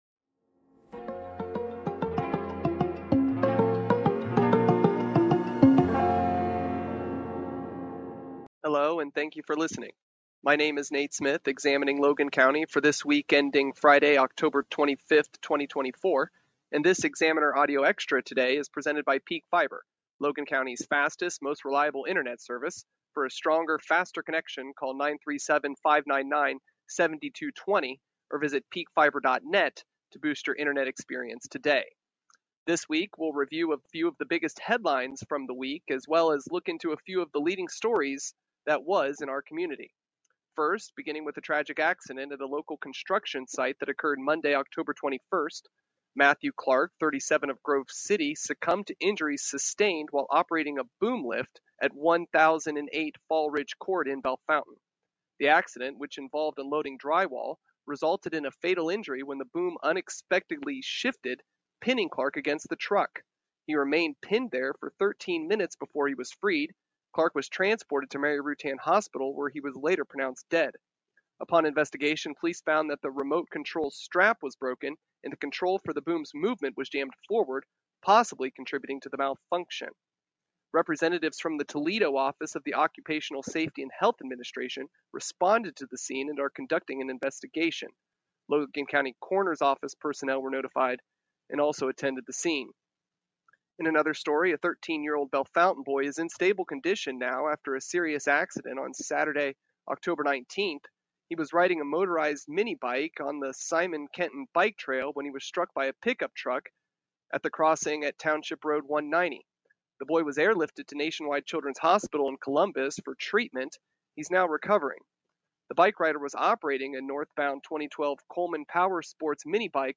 Some tornado victims that have been living in campgrounds since the March 14 storm are preparing to spend the cold winter in the temporary housing, hear from one local church volunteer about her efforts to meet the needs of those most impacted by the storm in this Examiner audio extra.
Also, hear from a couple school superintendents from the forum hosted by the Logan County Chamber of Commerce at Ohio Hi-Point, and a recap of news making headlines this week in Logan County.